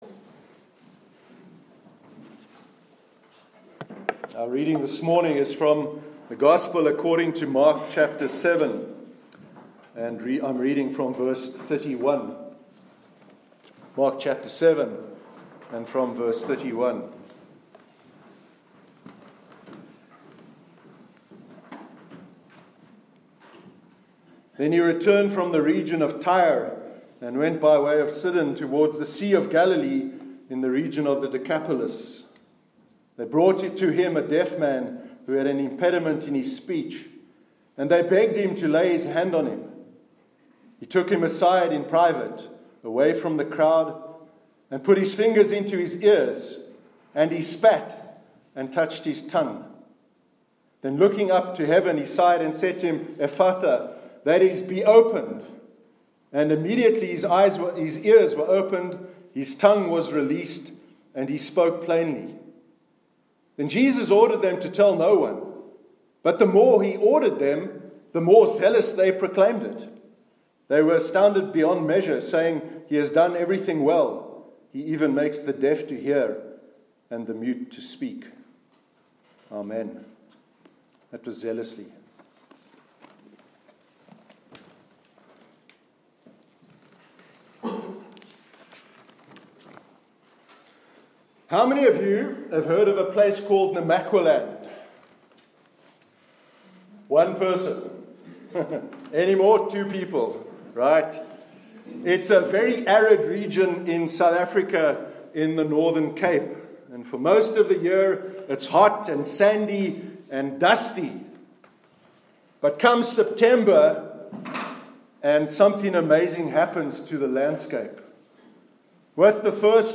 Sermon on the Life Affirming Ministry of Jesus